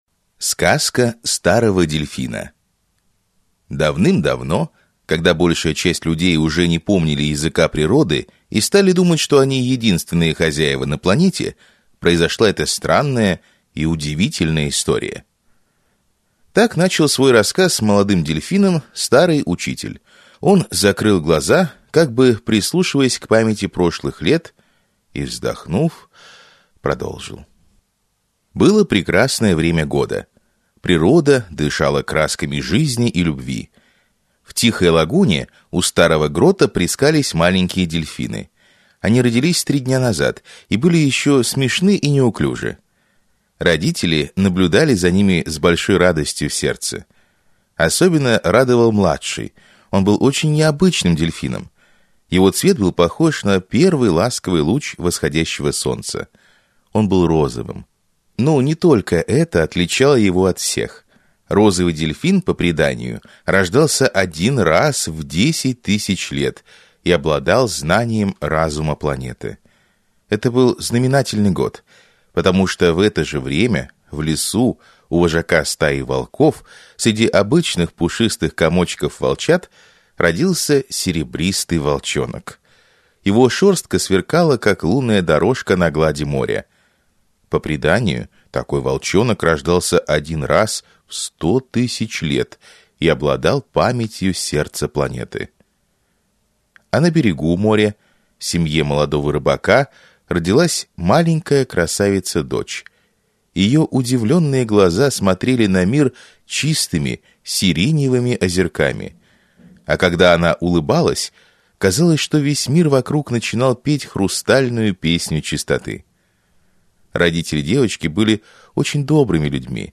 Аудиокнига Древнее пророчество | Библиотека аудиокниг
Прослушать и бесплатно скачать фрагмент аудиокниги